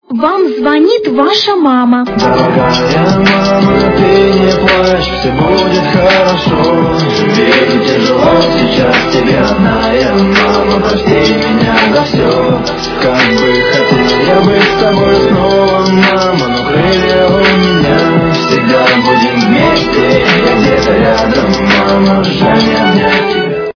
При прослушивании Звонок от мамы - Вам звонит Ваша мама качество понижено и присутствуют гудки.